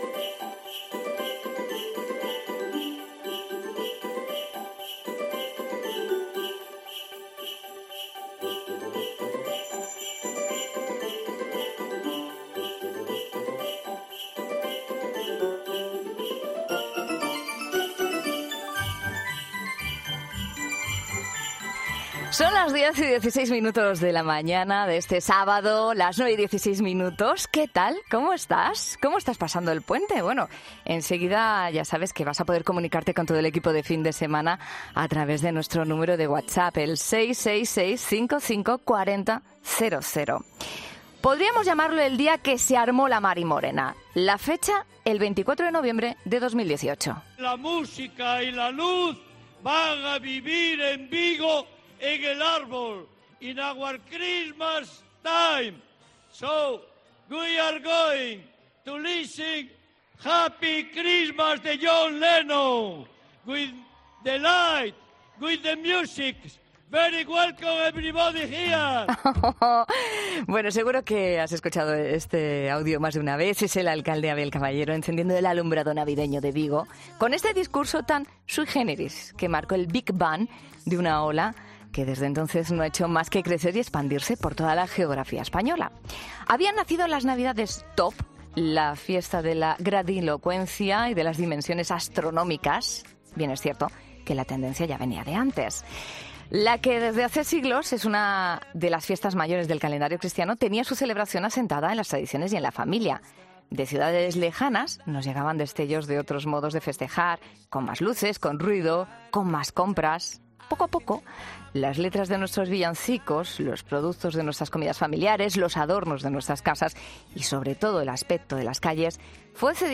En 'Fin de Semana' damos un paseo por Madrid, Vigo o Málaga y hablamos con un sociólogo sobre las consecuencias de estas aglomeraciones